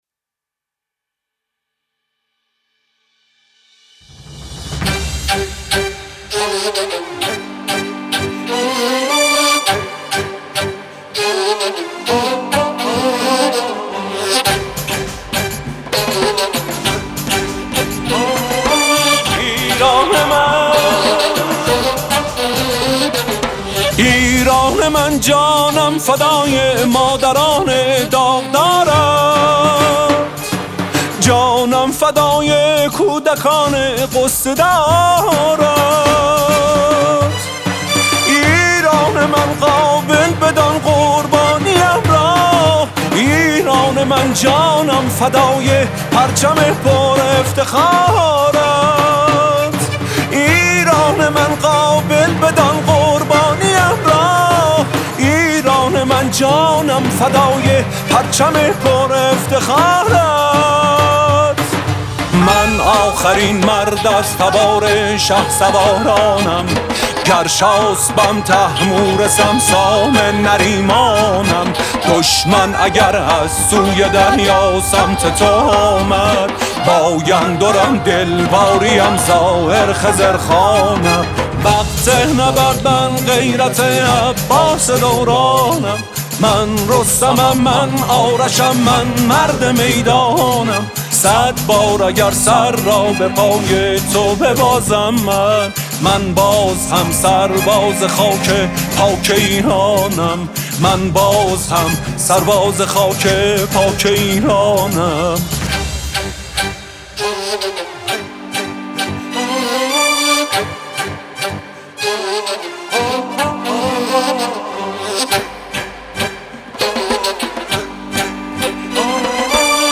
موزیک حماسی